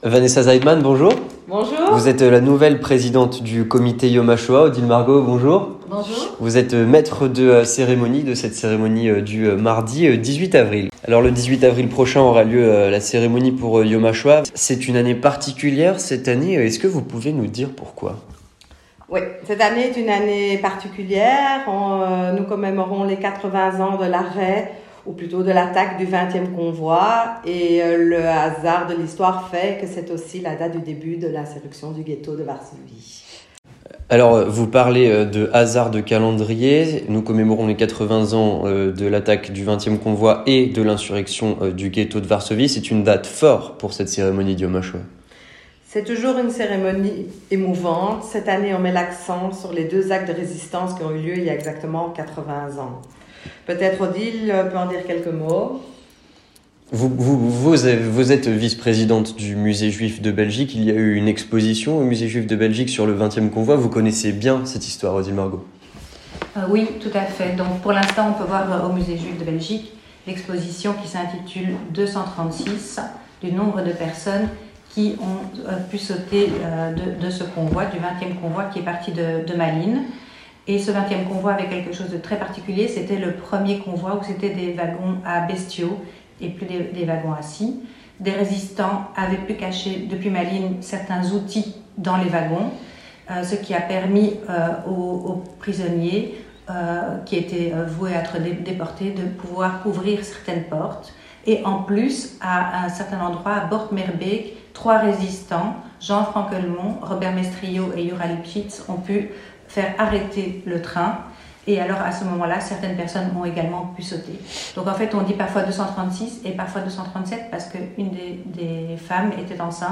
Entretien du 18h - La cérémonie de Yom Hashoah